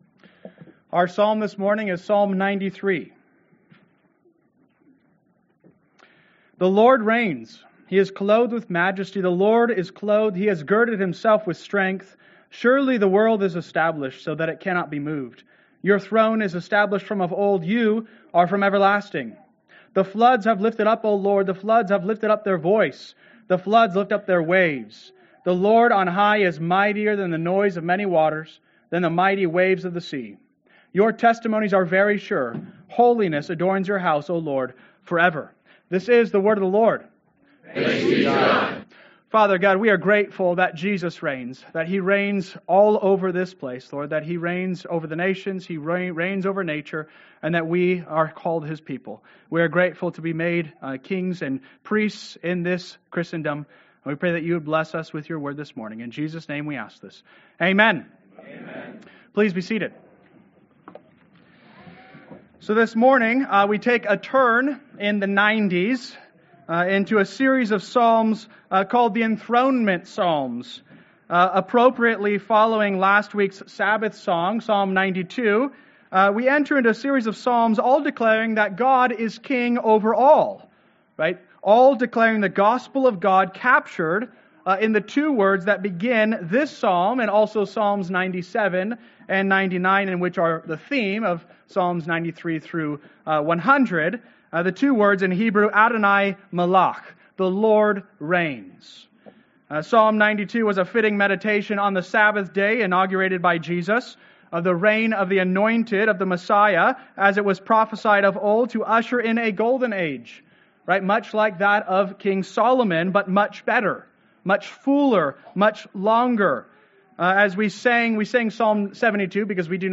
Sermon Outline: Jesus in the Psalms Part XCIII – Jesus Reigns-Outline